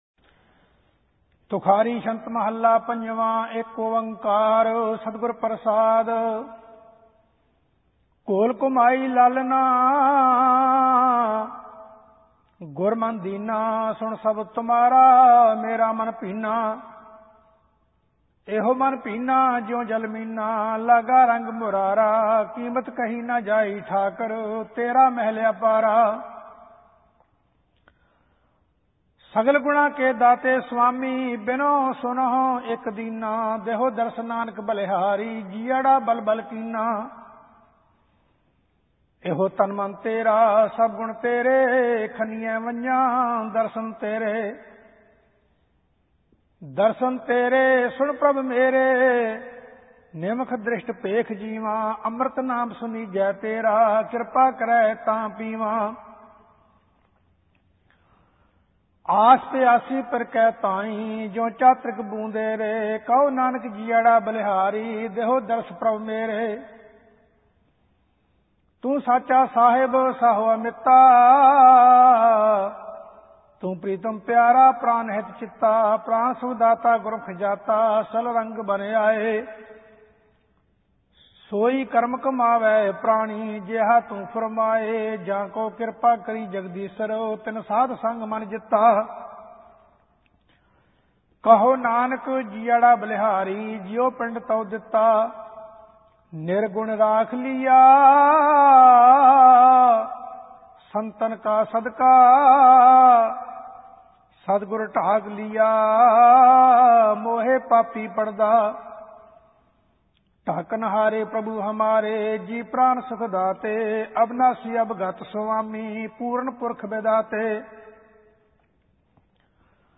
ਸਹਿਜ ਪਾਠ ਸ਼੍ਰੀ ਗੁਰੂ ਗ੍ਰੰਥ ਸਾਹਿਬ ਜੀ ਭਾਗ 13